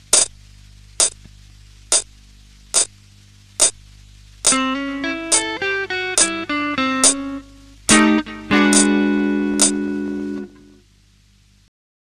Jazzitalia - Lezioni: Chitarra Blues - Intro, turnaround e finali standard
esempio in tonalita di A intro o turnaround